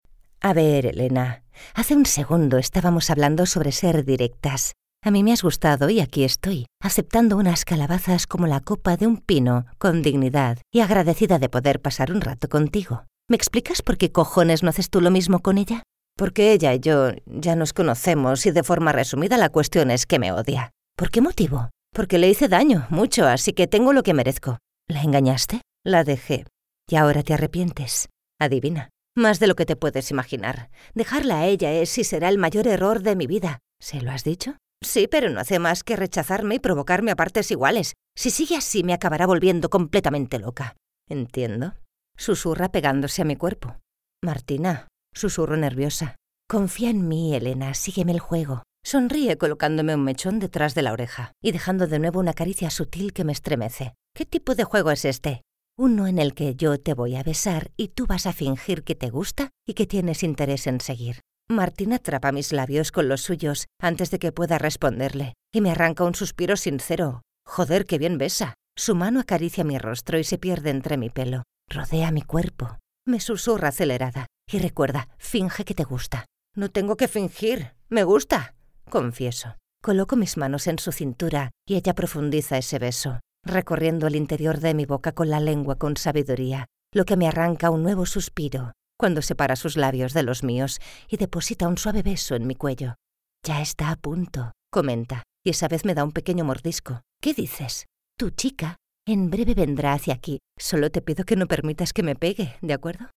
Audiolibro Tachando días (Ticking off Days)